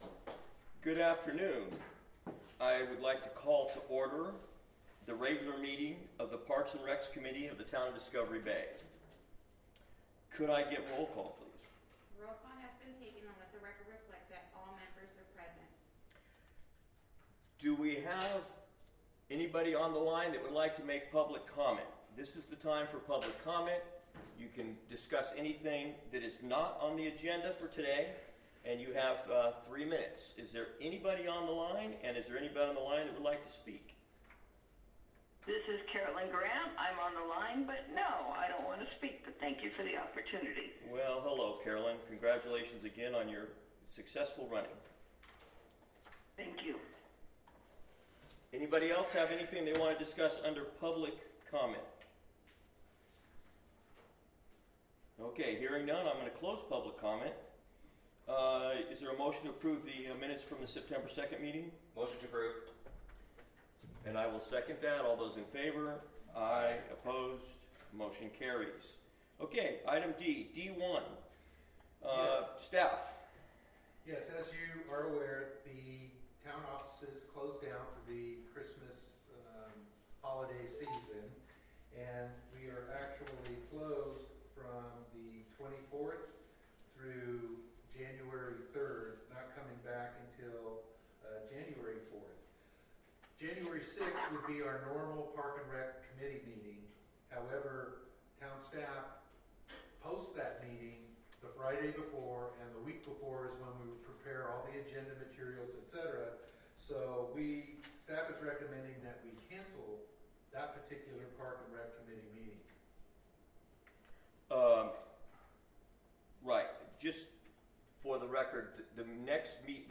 Parks & Recreation Committee - Special Meeting